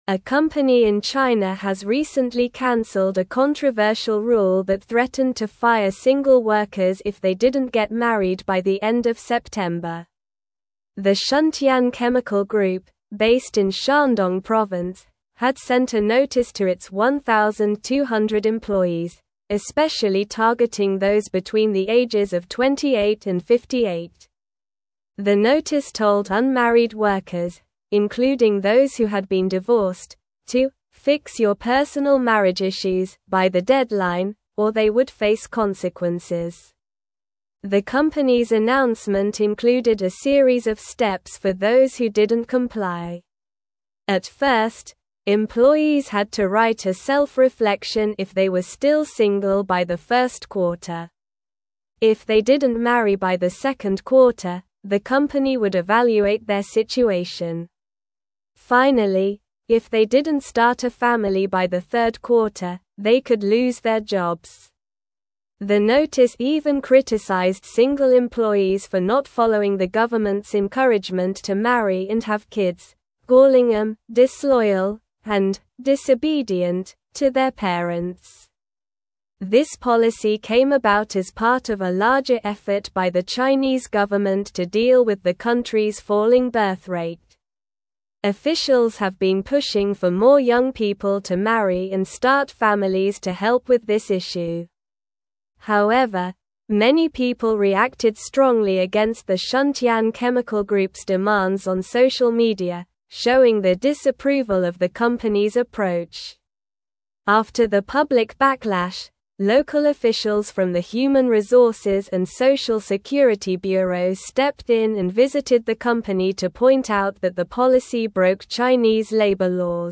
Slow
English-Newsroom-Upper-Intermediate-SLOW-Reading-Company-Faces-Backlash-Over-Controversial-Marriage-Policy.mp3